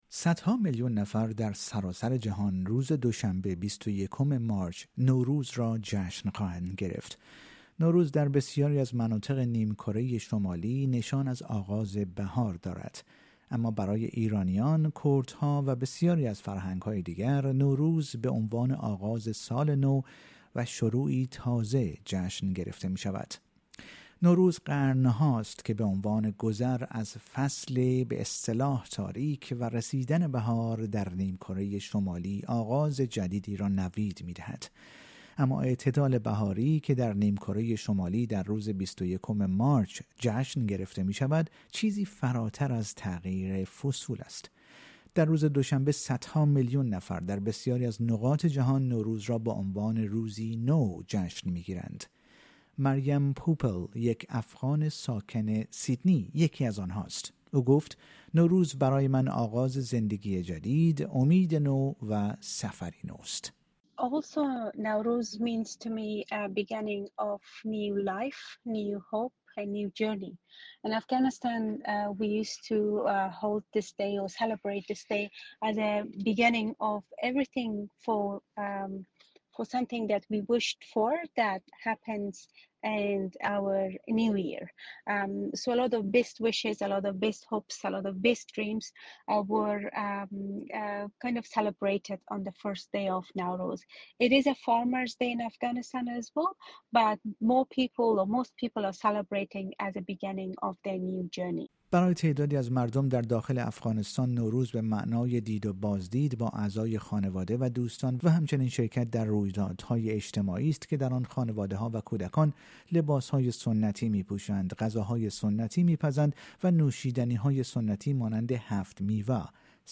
این گزارش رادیو اس بی اس فارسی